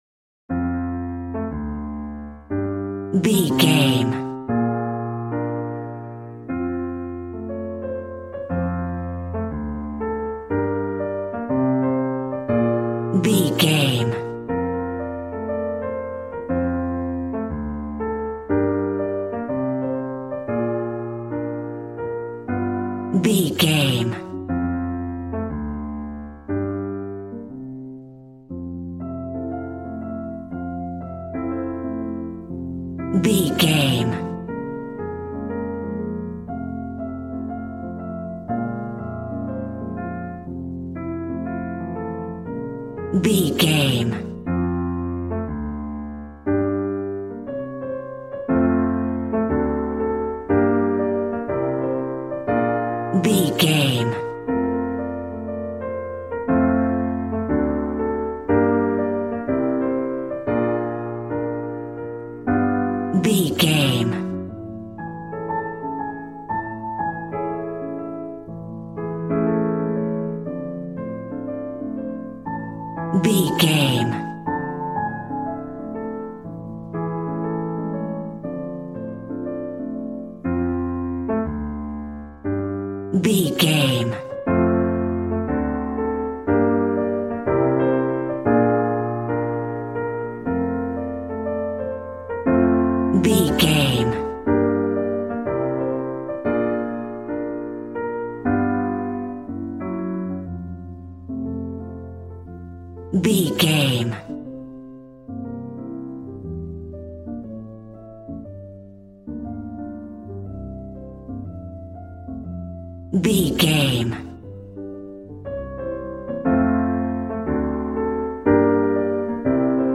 Smooth jazz piano mixed with jazz bass and cool jazz drums.,
Aeolian/Minor
D
drums